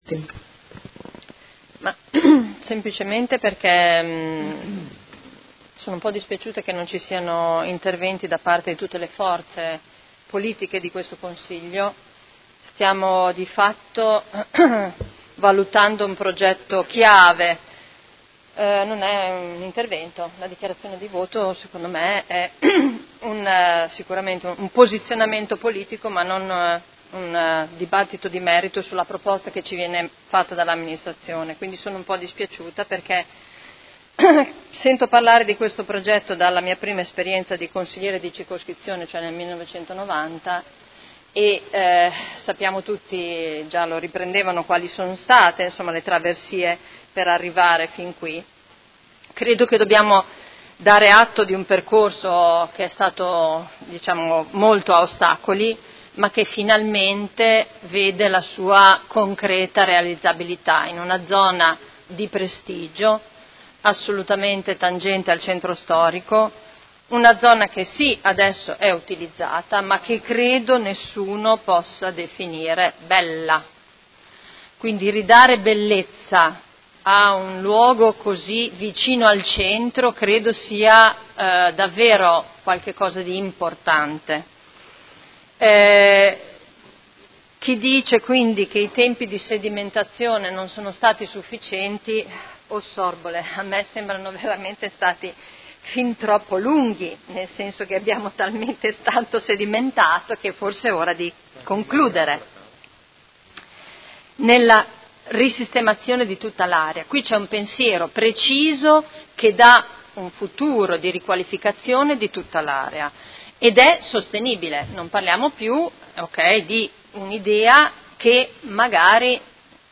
Seduta del 17/12/2018 Dibattito.